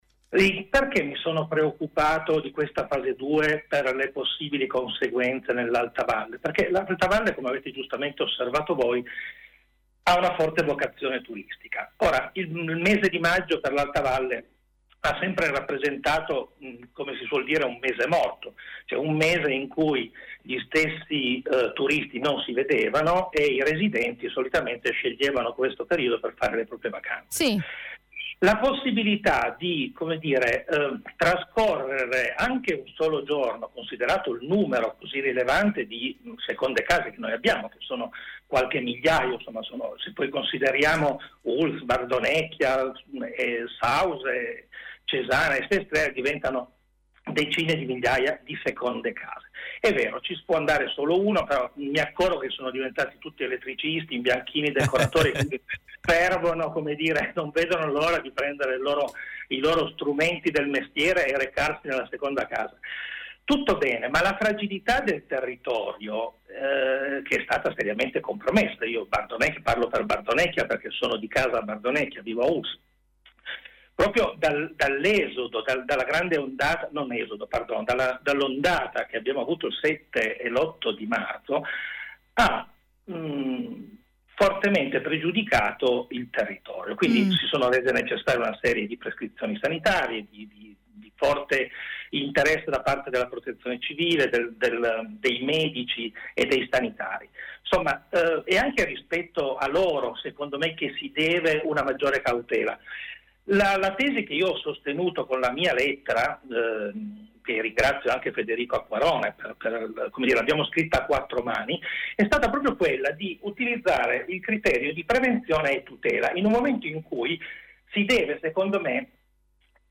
Ospite telefonico a Radio Dora